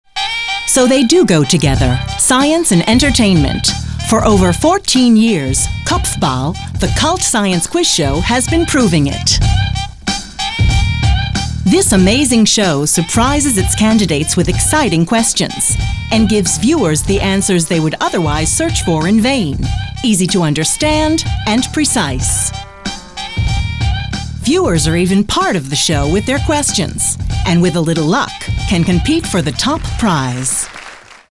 Sehr vielseitige Stimme - von warm bis hell; von mittel-tief bis hoch.
englische Sprecherin.
Sprechprobe: Sonstiges (Muttersprache):
English (US), female voiceover artist.